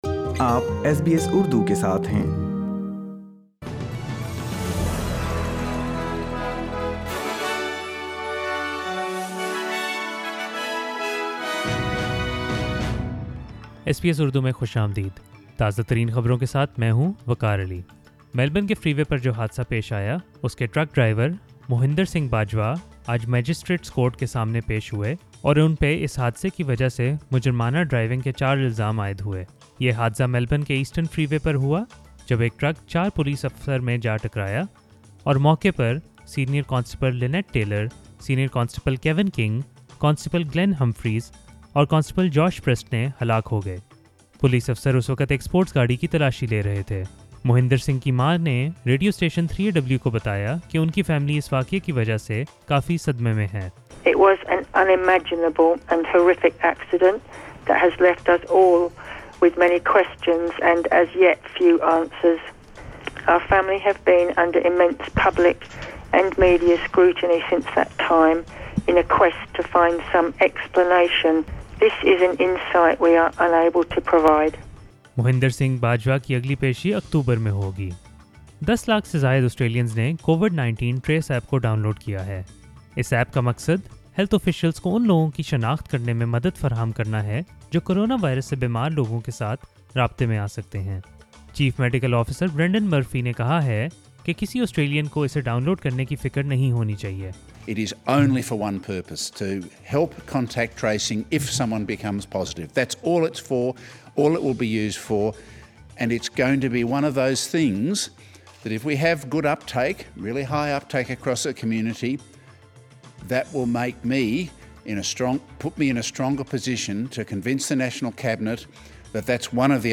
urdu_news_27th_april.mp3